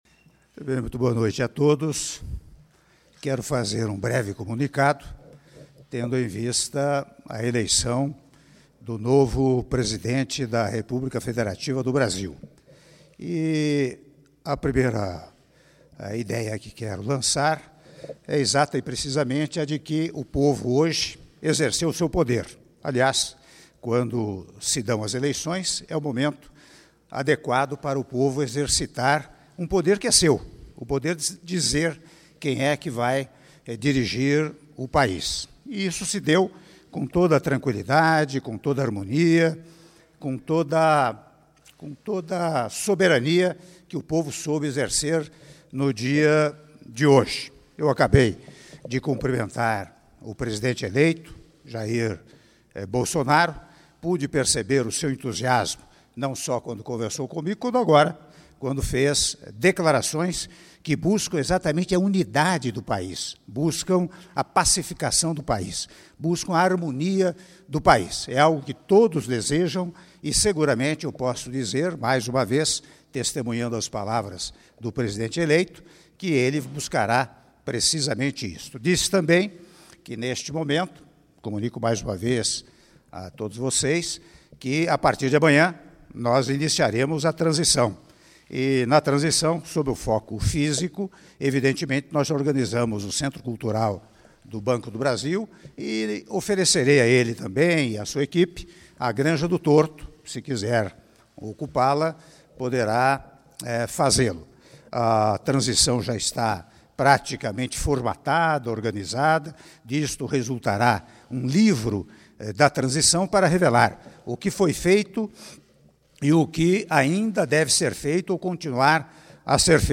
Áudio do pronunciamento à imprensa do Presidente da República, Michel Temer, após o resultado das eleições do segundo turno -Brasília/DF- (05min51s)